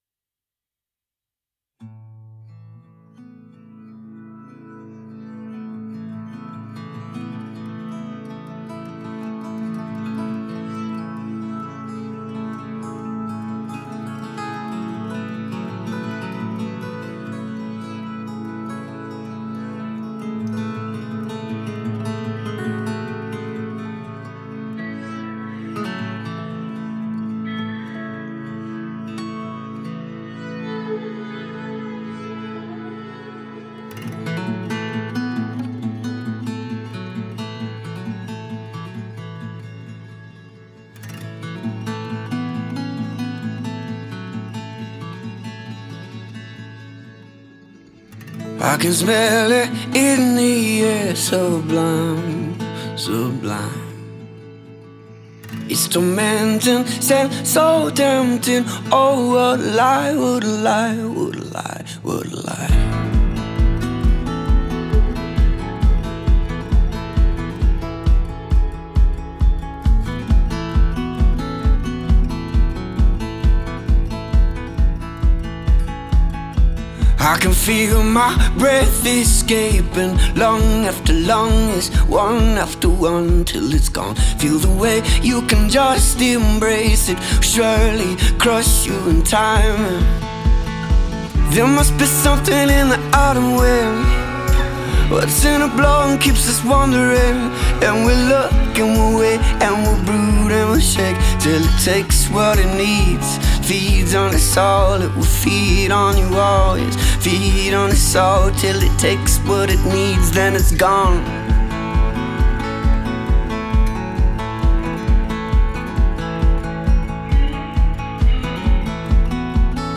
• Жанр: Folk